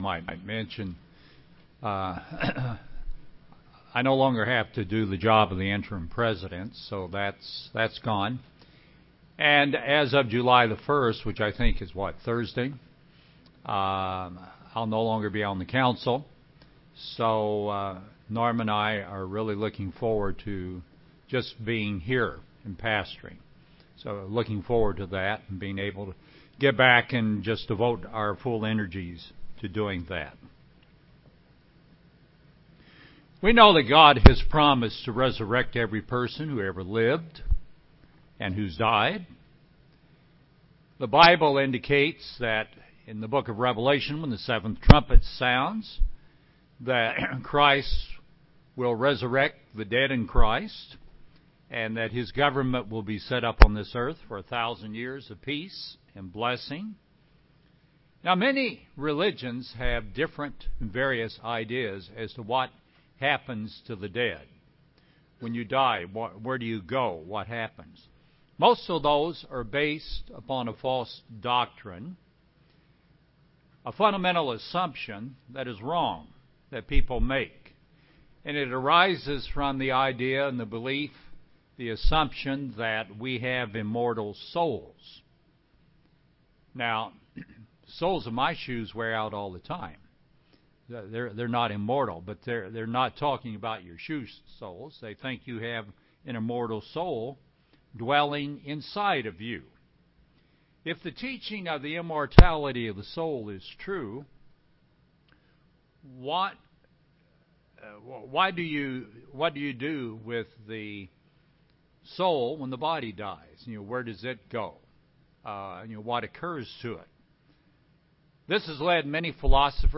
The teaching of the immortal soul is a counterfeit by Satan that clouds the truth of the matter. This sermon looks at the false teaching and explains the truth of the matter, that we are not yet immortal, but one day can be.r